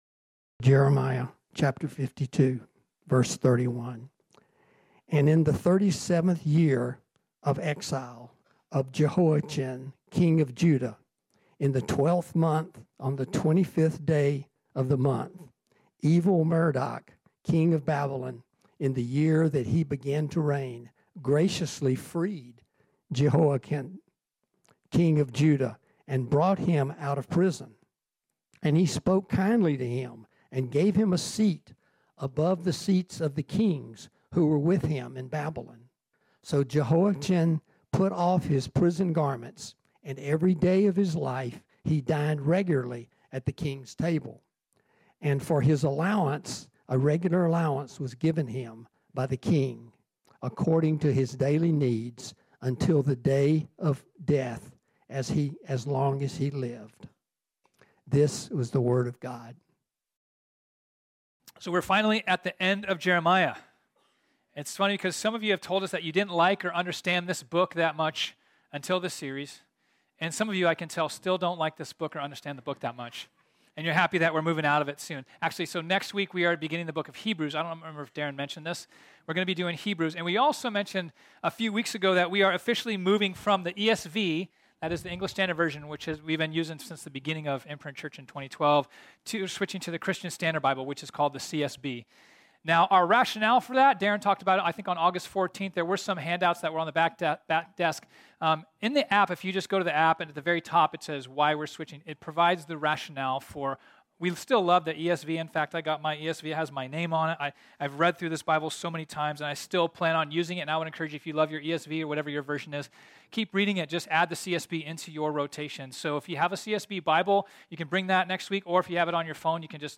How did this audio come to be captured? This sermon was originally preached on Sunday, September 4, 2022.